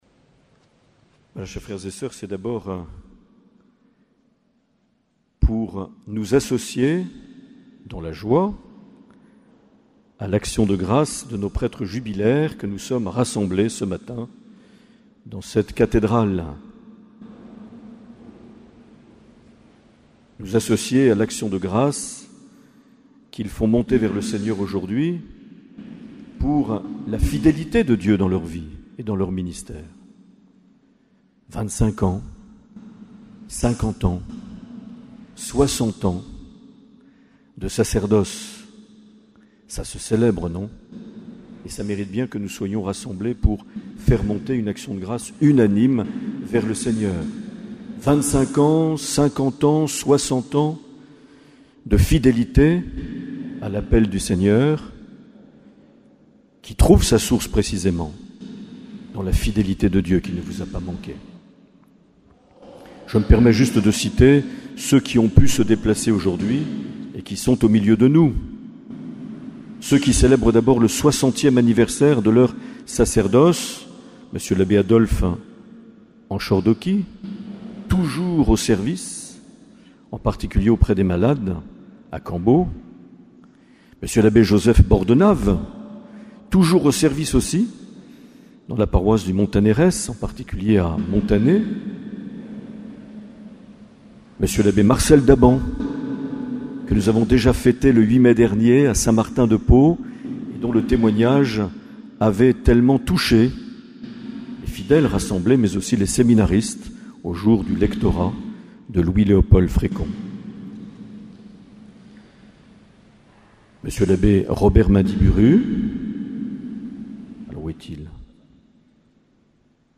24 juin 2015 - Cathédrale de Bayonne - Messe avec les prêtres jubilaires et admission des candidats au sacerdoce.
Accueil \ Emissions \ Vie de l’Eglise \ Evêque \ Les Homélies \ 24 juin 2015 - Cathédrale de Bayonne - Messe avec les prêtres jubilaires et (...)
Une émission présentée par Monseigneur Marc Aillet